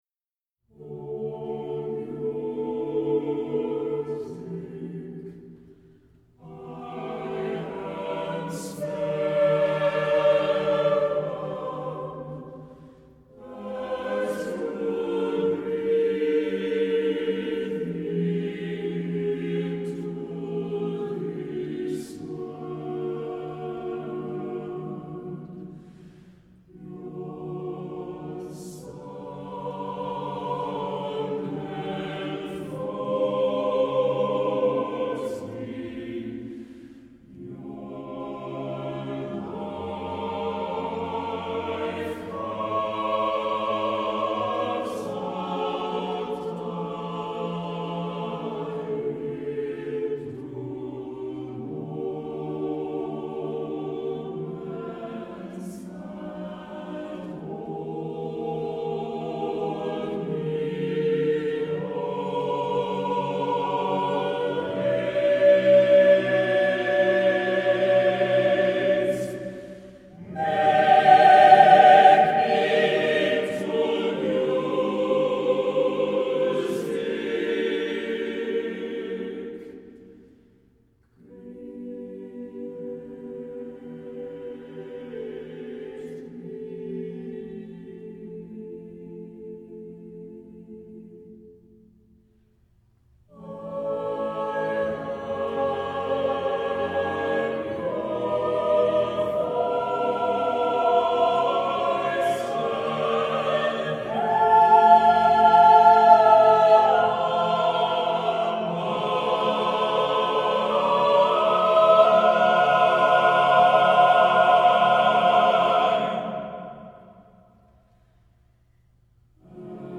Voicing: SSATBB a cappella